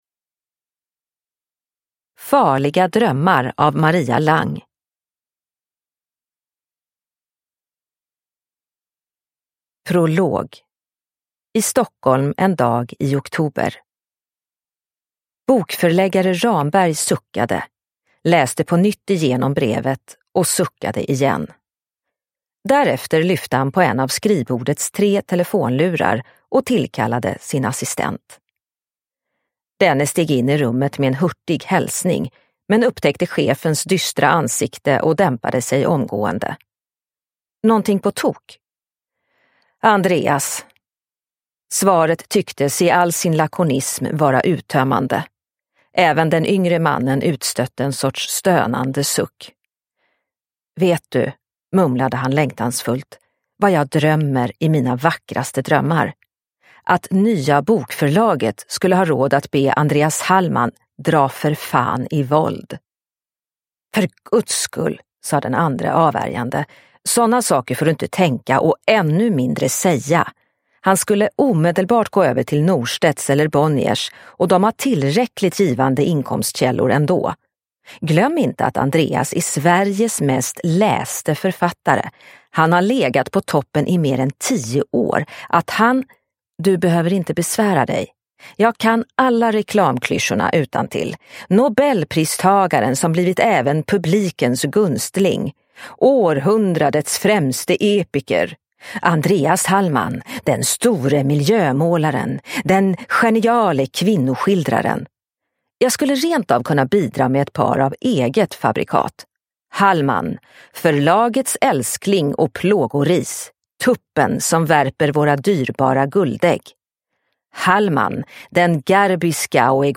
Farliga drömmar – Ljudbok – Laddas ner